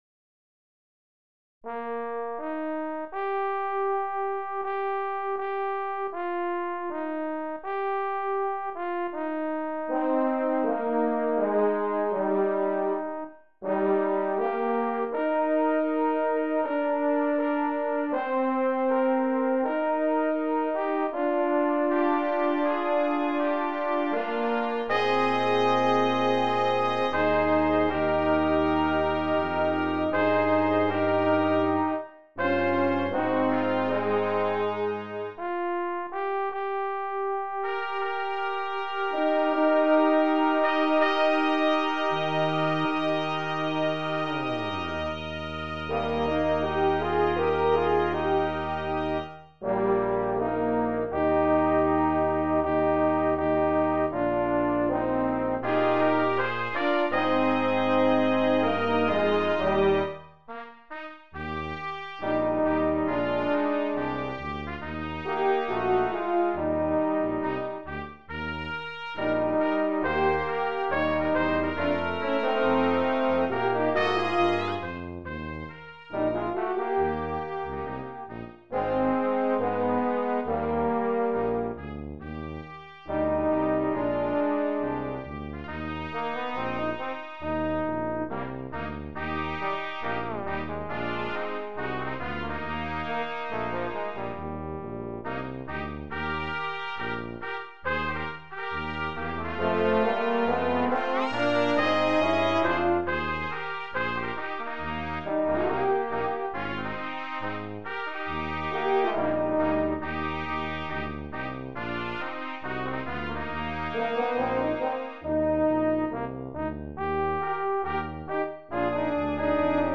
Brass Quintet
traditional spiritual